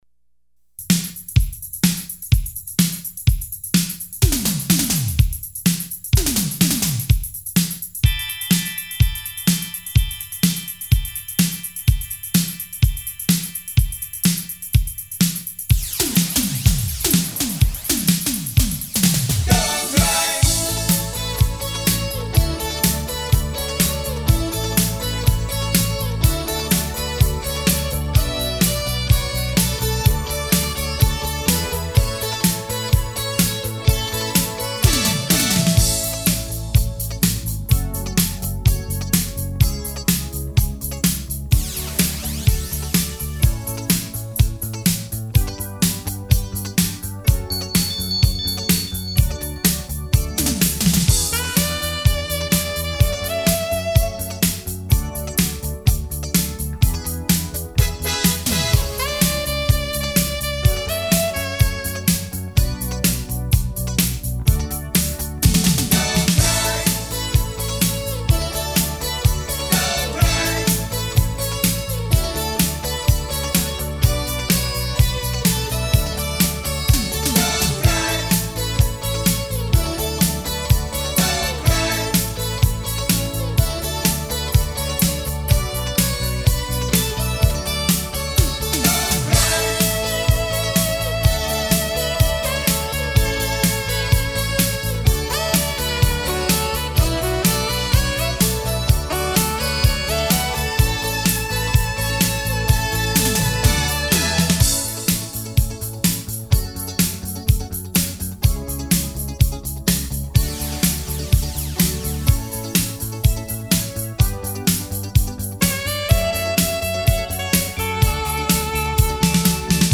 的士高